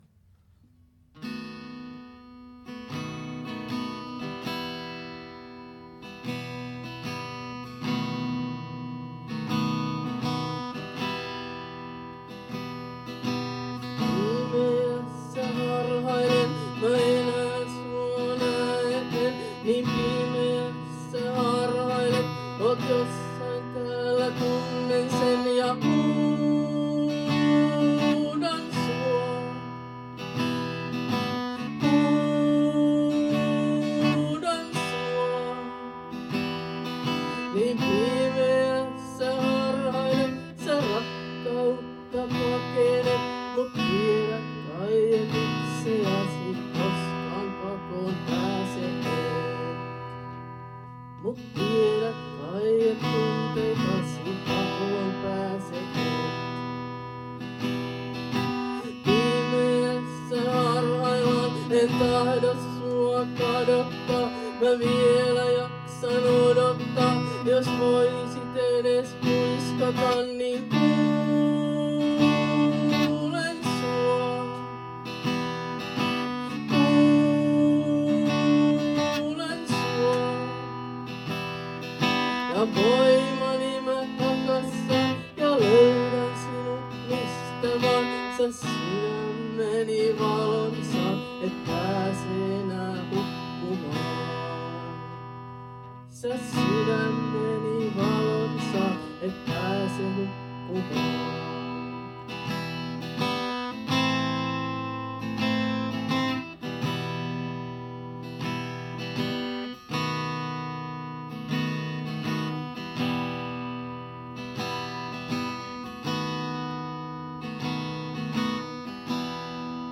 Laulu